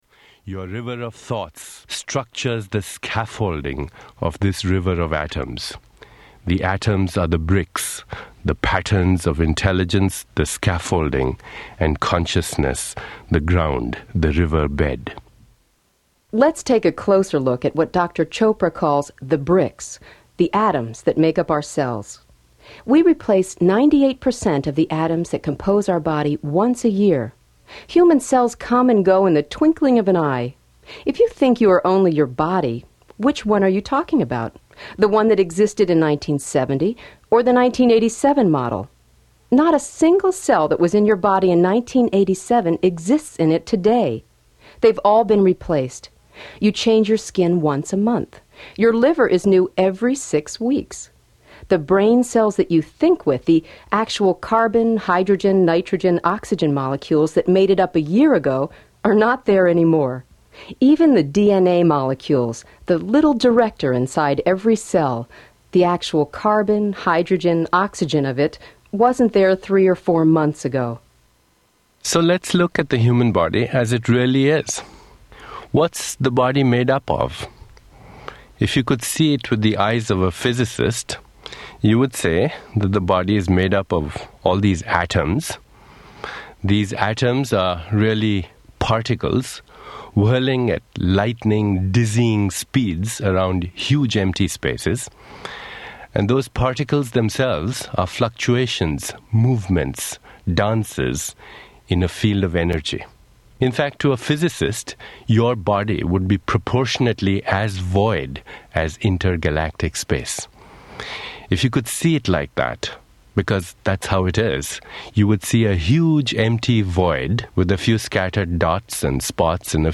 Tags: Quantum Physics Audio Books Quantum Physics Quantum Physics clips Physics Quantum Physics sound clips